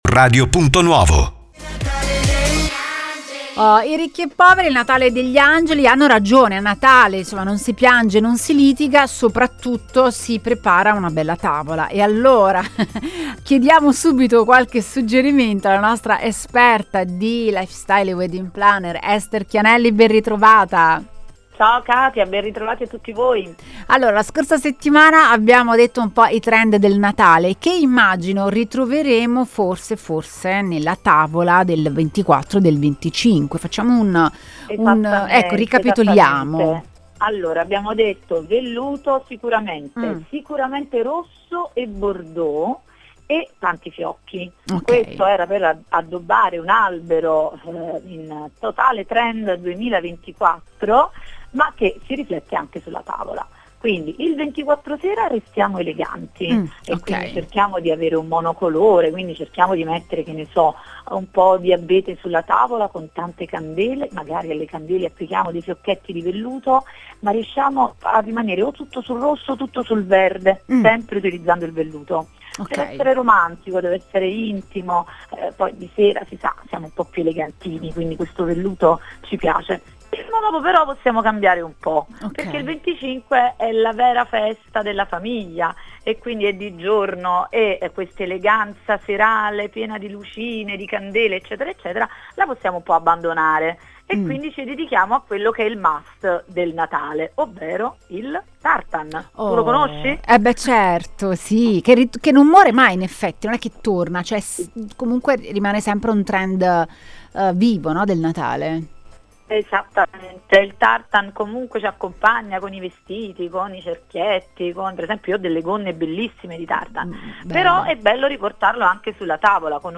Abbiamo fatto due chiacchere con la wedding planner ed esperta di trend